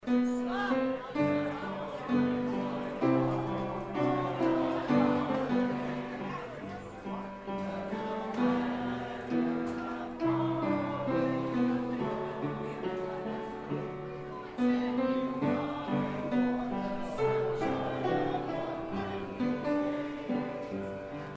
The Riley County Seniors’ Service Center was full of smiling, festive faces for their annual Thanksgiving dinner.
Volunteers served up plates full of turkey, mashed potatoes, gravy, vegetables, and pumpkin pie while the Center Singers sang some holiday tunes.
The Center Singers perform “There’s No Place Like Home”